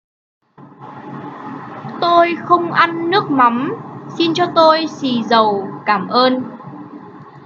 實用越語教學
越語發音教學由非凡教育中心提供